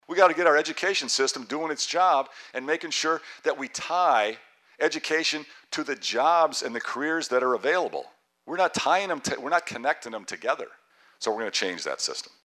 Rauner spoke to QC Chamber of Commerce members Tuesday in Rock Island.